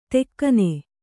♪ tekkane